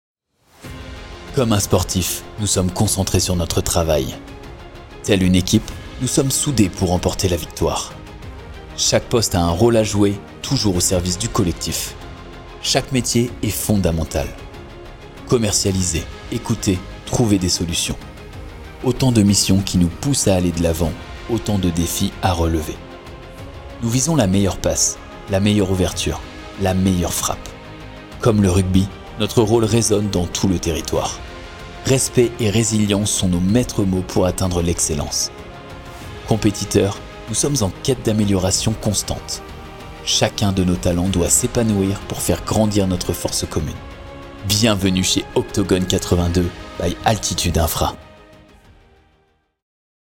25 - 50 ans - Baryton-basse